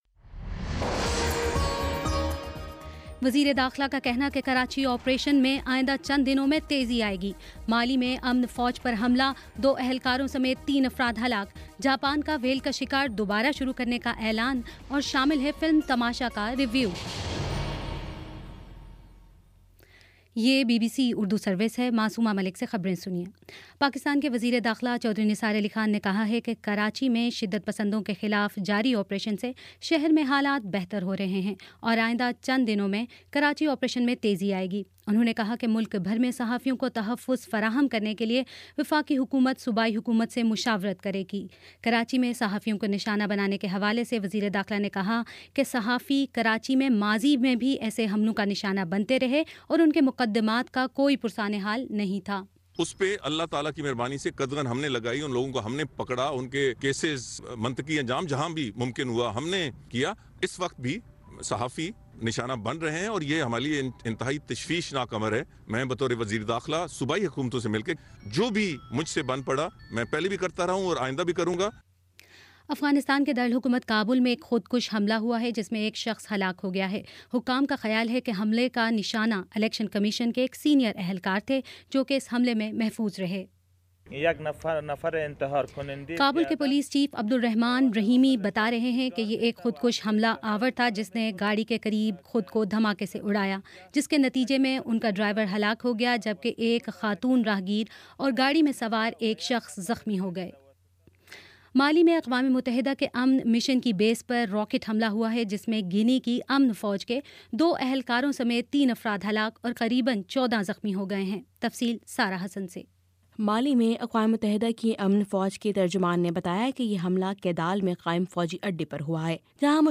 نومبر 28 : شام پانچ بجے کا نیوز بُلیٹن